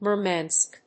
/mɝmænsk(米国英語), mɜ:mænsk(英国英語)/